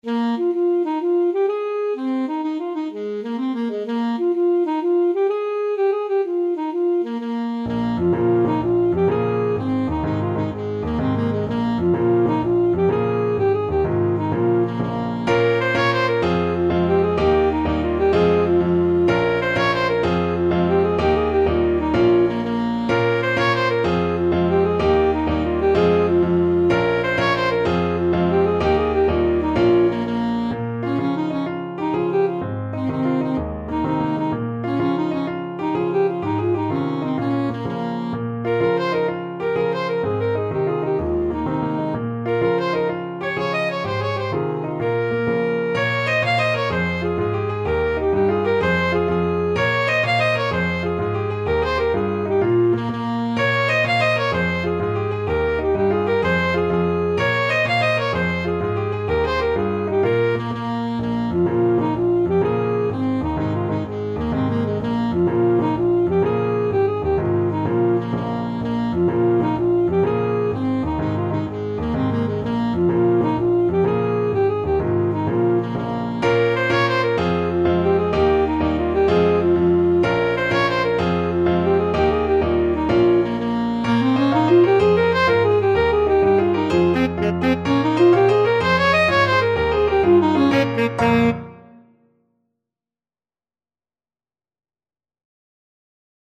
Free Sheet music for Alto Saxophone
Traditional Music of unknown author.
F minor (Sounding Pitch) D minor (Alto Saxophone in Eb) (View more F minor Music for Saxophone )
Fast .=c.126
12/8 (View more 12/8 Music)
Irish